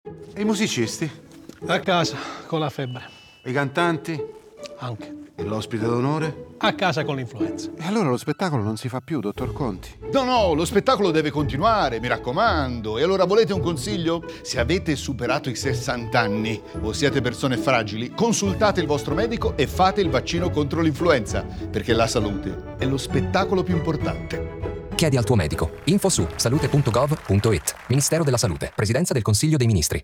Lo spot radio
spot-radio-vaccini-carlo-conti-30.mp3